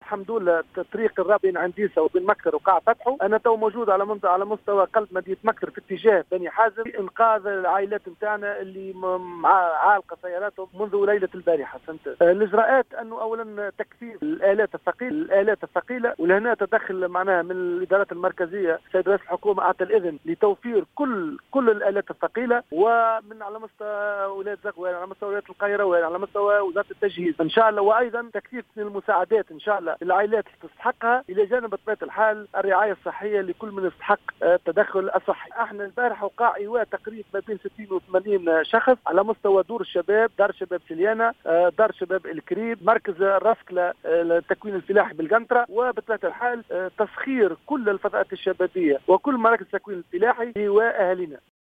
أكد والي سليانة علي سعيد في تصريح للجوهرة "اف ام" اليوم الجمعة 25 جانفي 2019 أن الطريق الرابط بين "عين ديسة" ومكثر وقع اعادة فتحه.